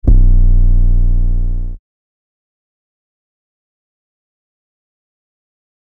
Metro 808 3 (C).wav